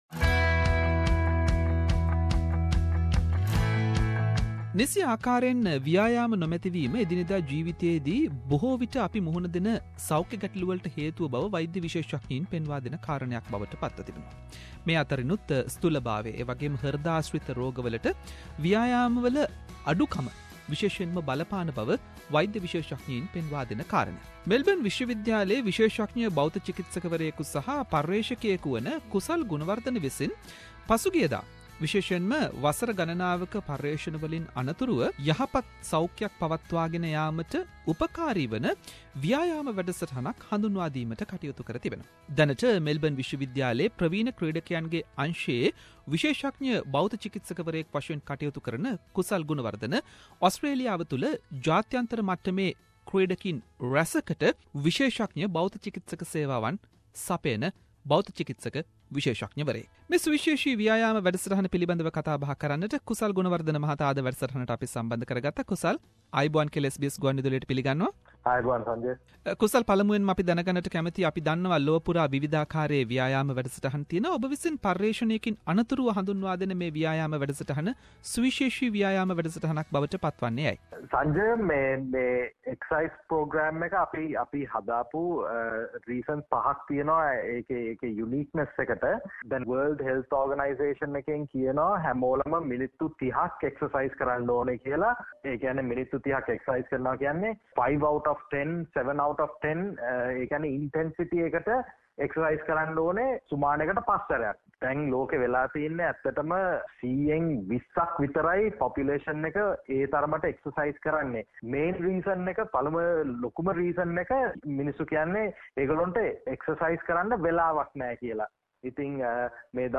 කතා බහක්